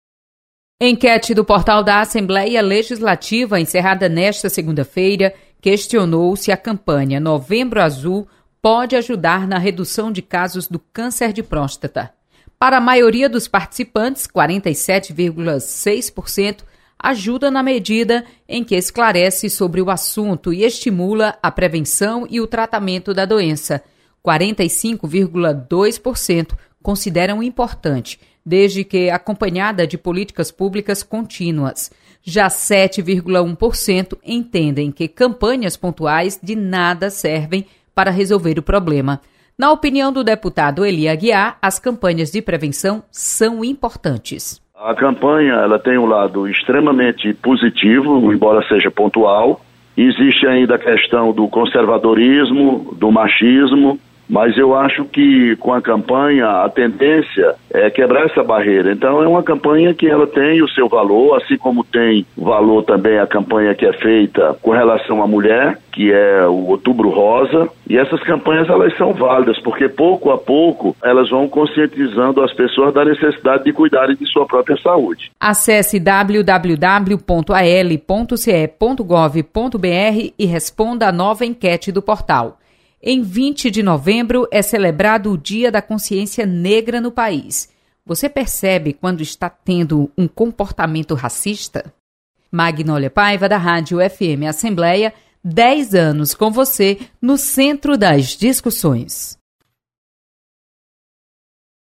Enquete